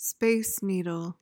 PRONUNCIATION:
(SPAYS need-uhl)